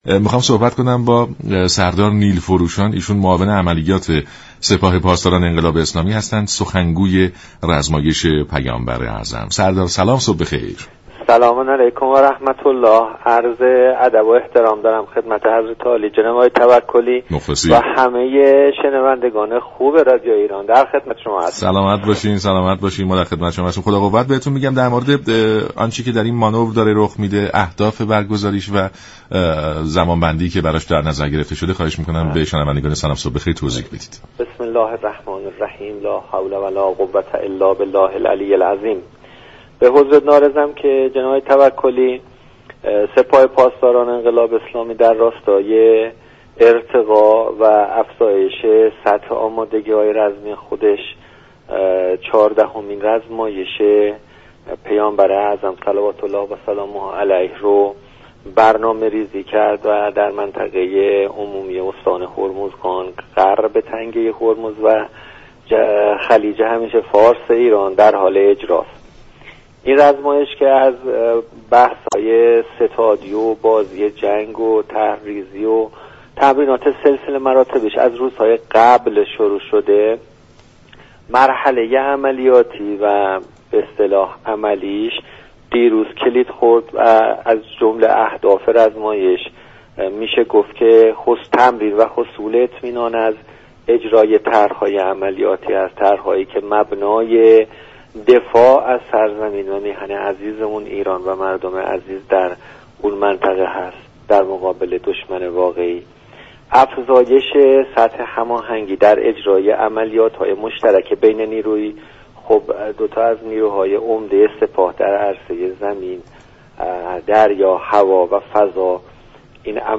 سردار نیلفروشان در رادیو ایران: رزمایش پیامبر اعظم (ص) نماد اقتدار ایران در منطقه و جهان